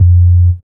08SYN.BASS.wav